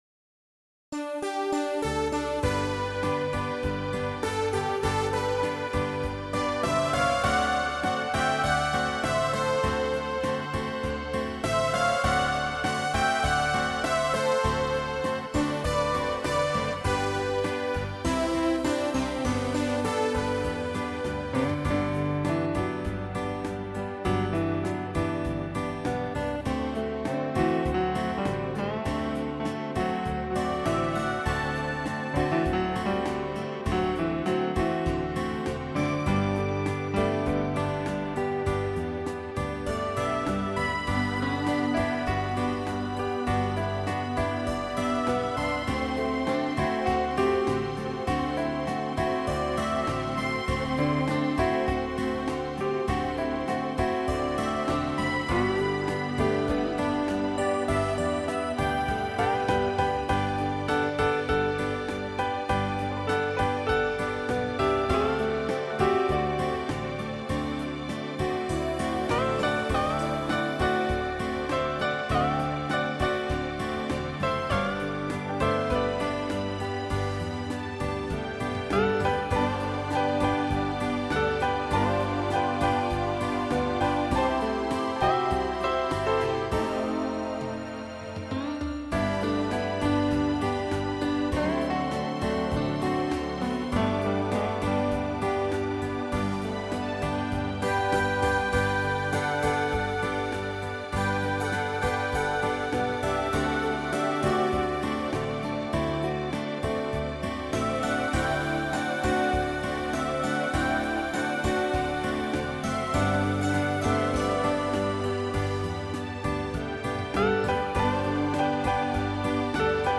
9/ Latino
latino melódie  upravené pre hru na viac nástrojov